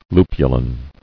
[lu·pu·lin]